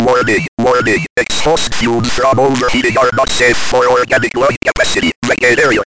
"Yo, mike check. *sounds of a feedback loop getting exponentially louder*" -Drake
This page contains an audio file that is either very loud or has high frequencies.